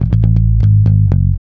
-MM BOOGFILL.wav